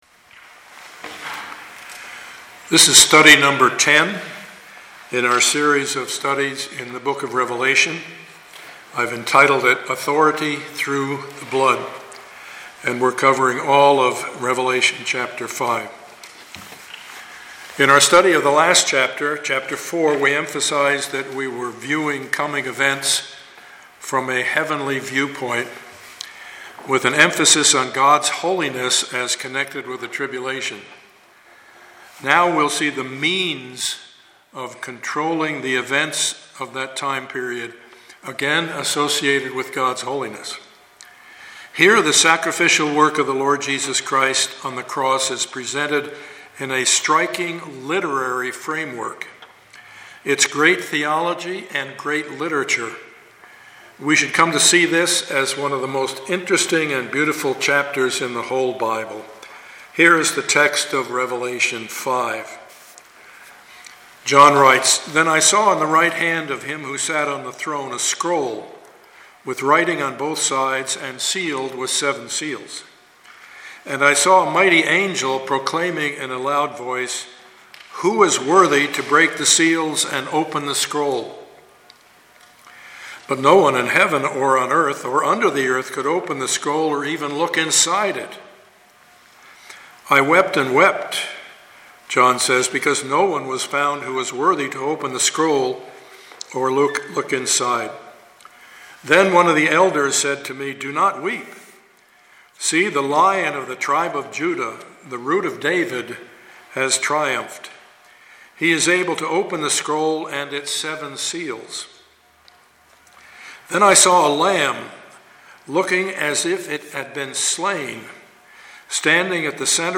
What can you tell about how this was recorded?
Passage: Revelation 5:1-14 Service Type: Sunday morning